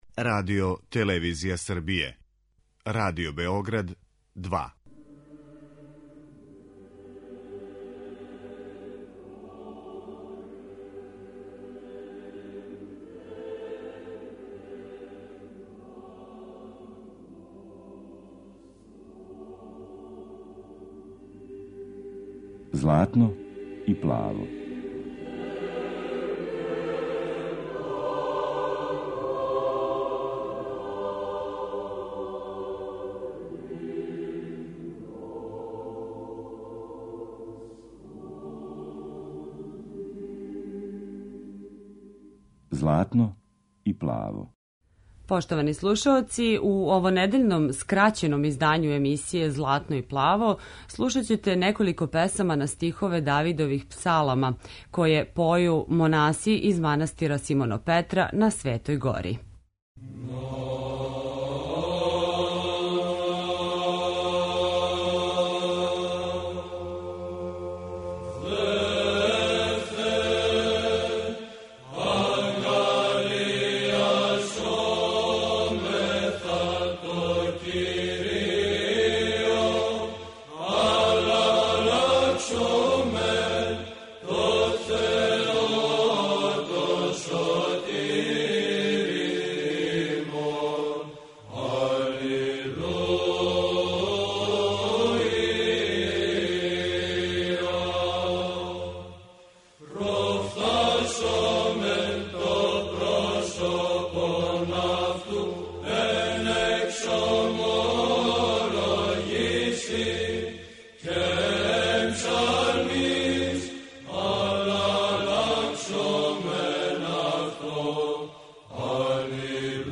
Ове суботе емитоваћемо неколико песама на стихове Давидових псалама, које поју монаси из манастира Симонопетра са Свете Горе.